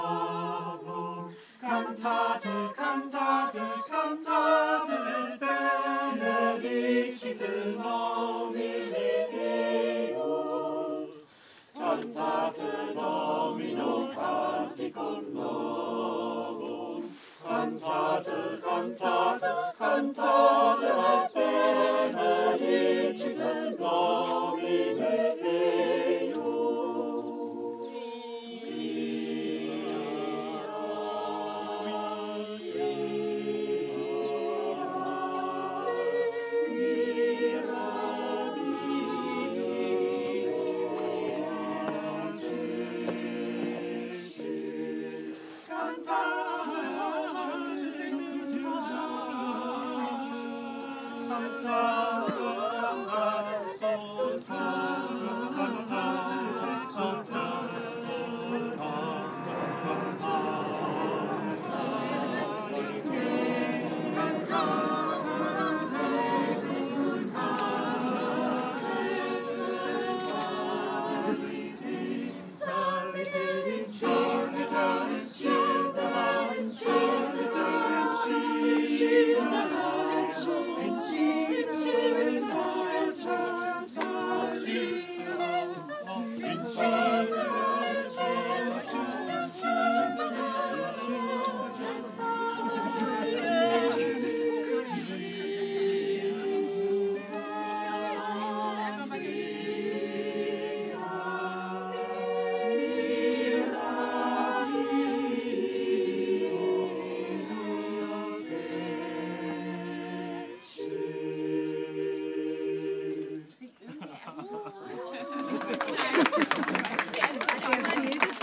Bach Chor Sonntag Brunch